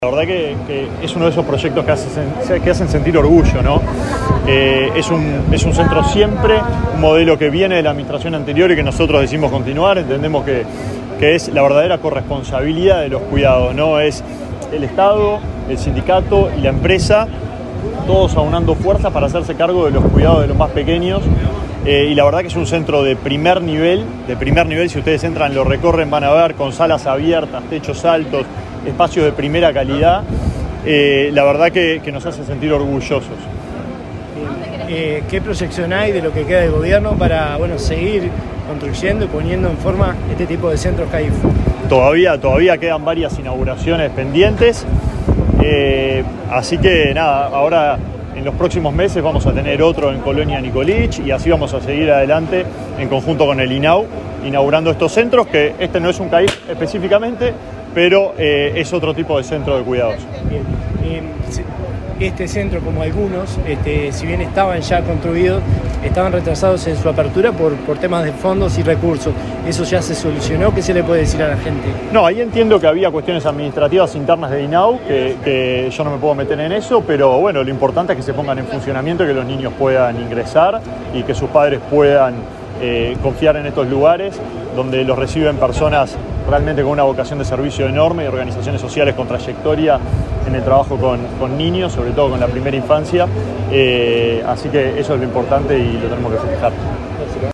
Declaraciones del ministro de Desarrollo Social, Alejandro Sciarra
Declaraciones del ministro de Desarrollo Social, Alejandro Sciarra 24/09/2024 Compartir Facebook X Copiar enlace WhatsApp LinkedIn Este martes 24 en Las Piedras, Canelones, el ministro de Desarrollo Social, Alejandro Sciarra, dialogó con la prensa, luego de participar en la inauguración de un centro Siempre, un servicio socioeducativo dirigido a la infancia, sus familiares y la comunidad.